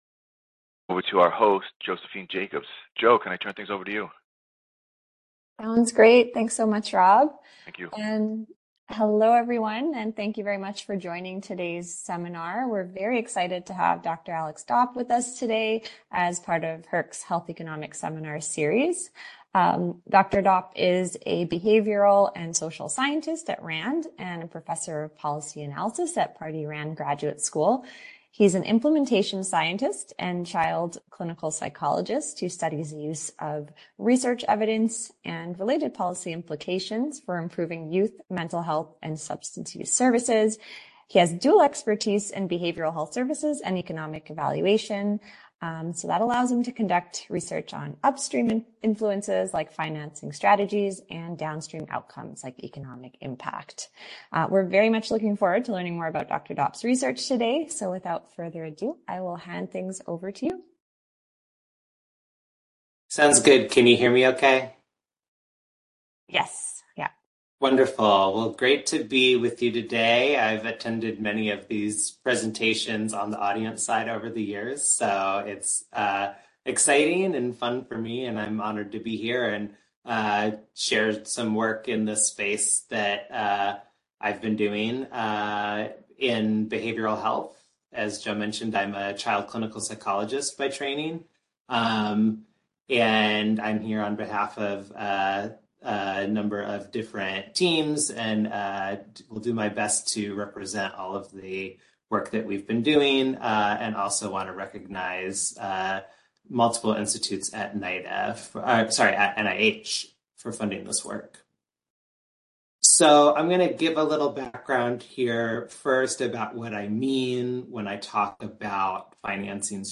HERC Health Economics Seminar